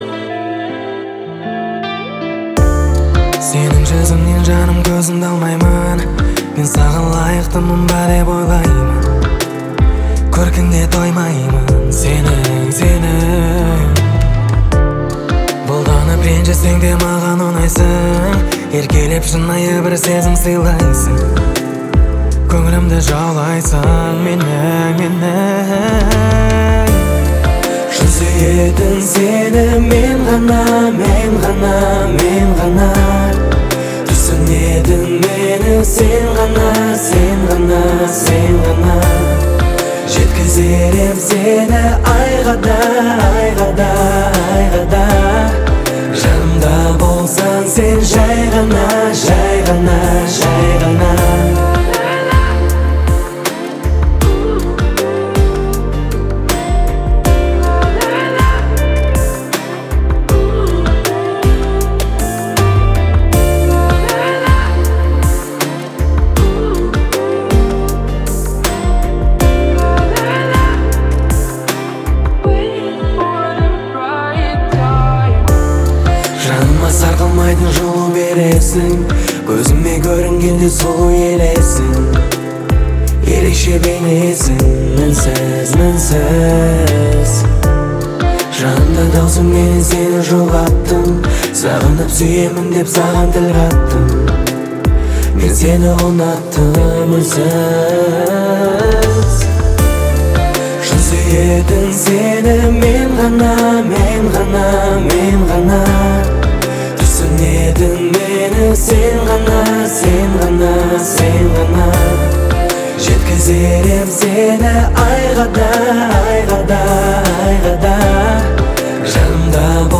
• Качество: 320, Stereo
поп
гитара
мужской голос
спокойные
романтичные